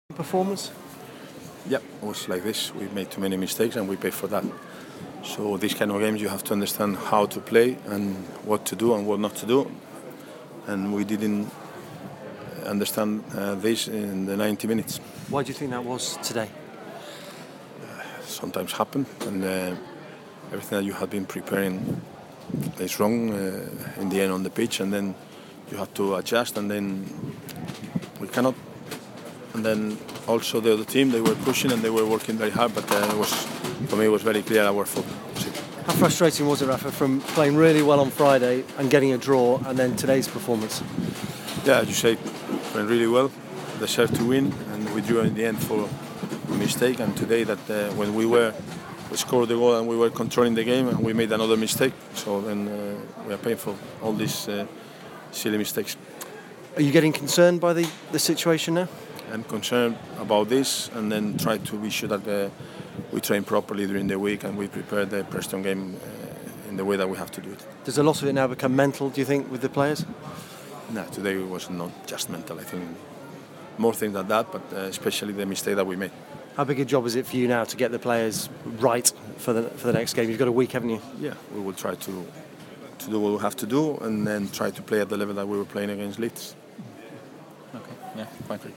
Rafa Benítez told the BBC that his side were punished for making too many mistakes on a hugely disappointing afternoon at Portman Road.